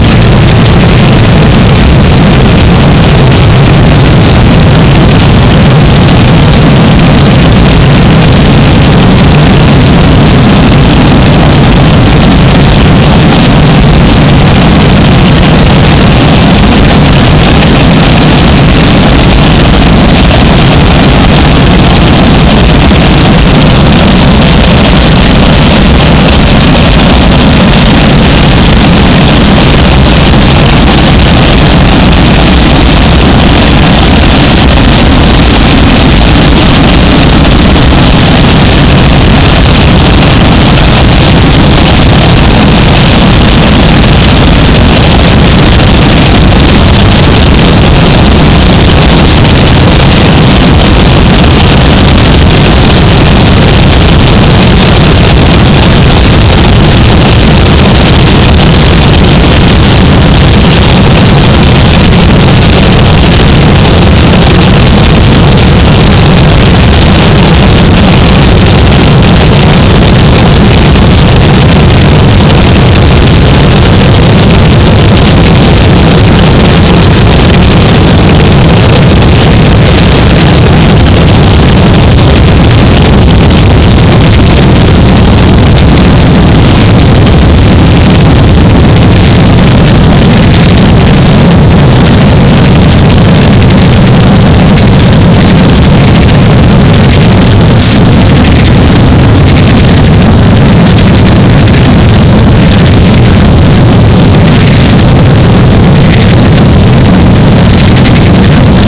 fire, crackling, voice
recorded live in dolni pocernice, prague on 16 july 2006